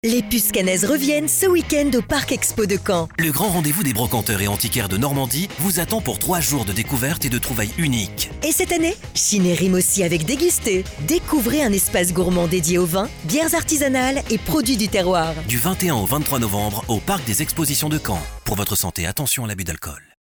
SPOT RADIO PUCES CAENNAISES 2025